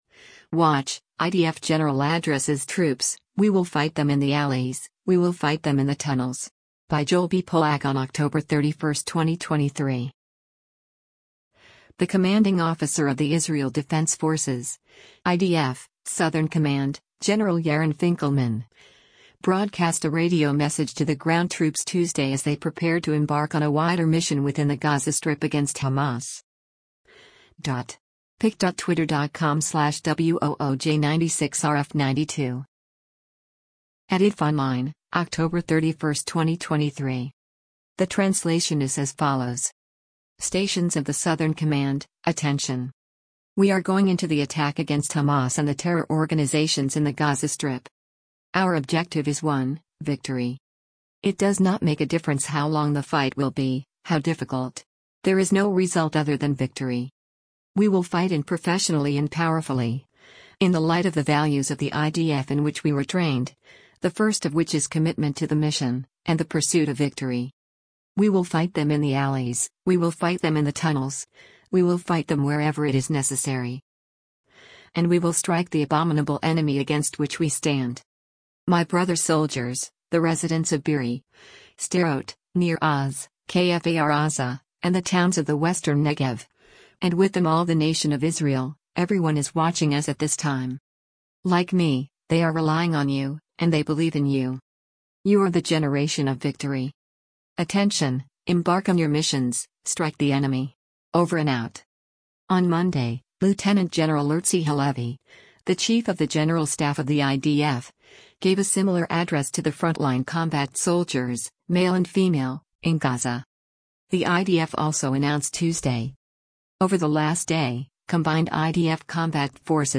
The commanding officer of the Israel Defense Forces (IDF) Southern Command, General Yaron Finkelman, broadcast a radio message to the ground troops Tuesday as they prepared to embark on a wider mission within the Gaza Strip against Hamas.